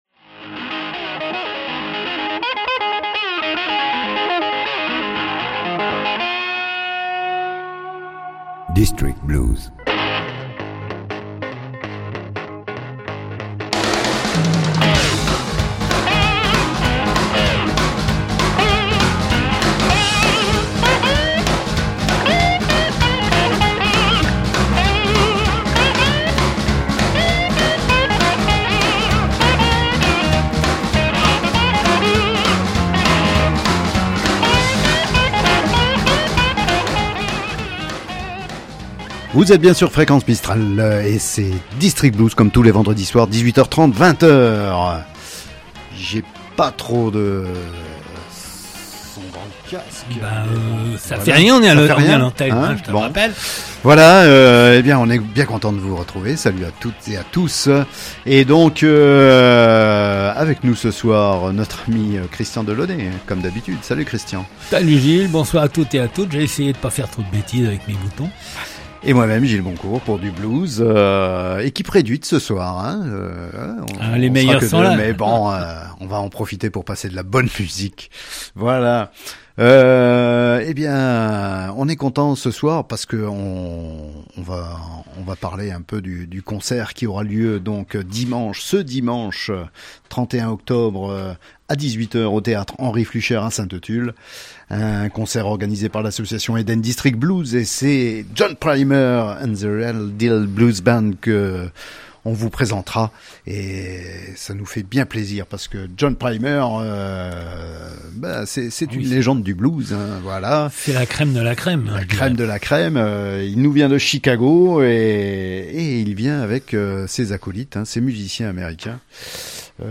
Le blues sous toutes ses formes, le blues sous toutes ses faces, voilà le credo d’Eden District Blues, qu’il vienne de Chicago, de Milan, du Texas ou de Toulouse, qu’il soit roots, swamp, rock ou du delta… Tout au long de l’année, EDB propose concerts, expos photos, conférences, cinéma, concerts pour les écoles… et anime une émission radio « District Blues ». « DISTRICT BLUES », une émission hebdomadaire, tous les vendredis à 18h30 et rediffusée le mercredi à 23h00 .